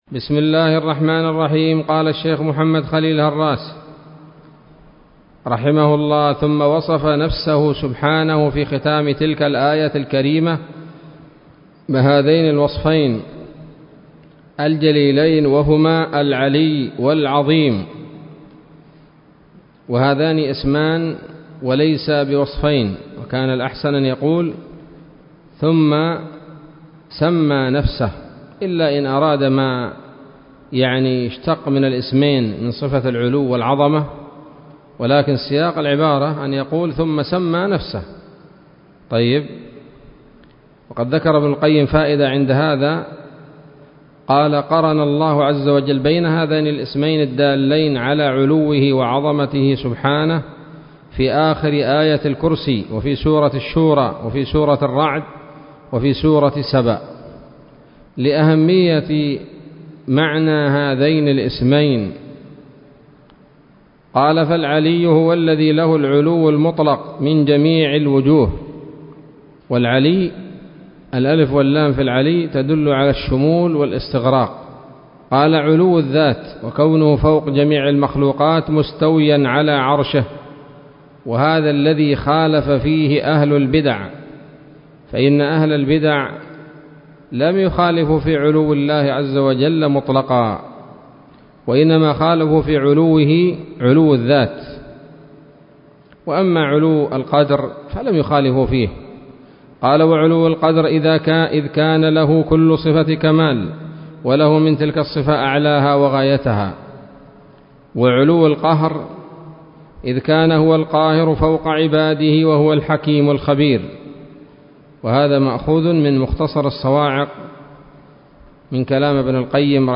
الدروس العلمية شرح العقيدة الواسطية للهراس [1442 هـ] شروح العقيدة
الدرس الثاني والأربعون من شرح العقيدة الواسطية للهراس